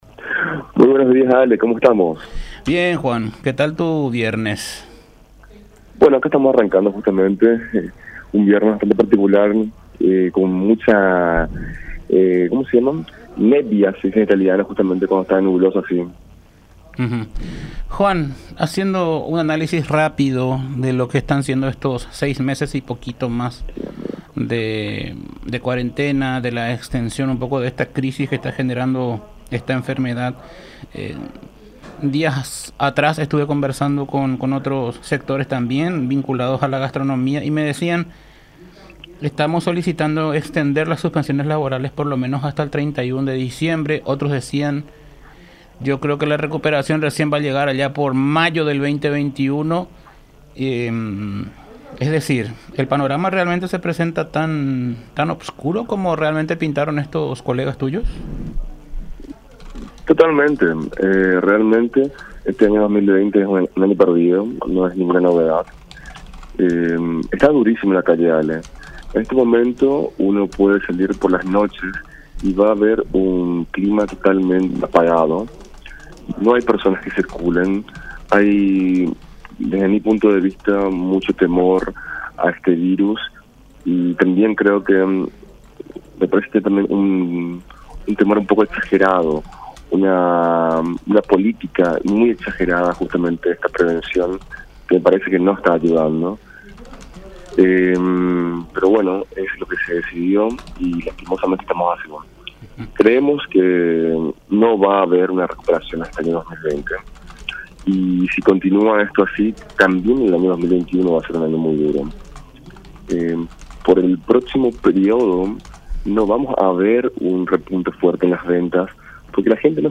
“Creemos que no va haber una recuperación en este año 2.020 y si continua esto así, también el año 2.021 va ser una año muy duro” mencionó el empresario gastronómico en diálogo con La Unión R800 AM.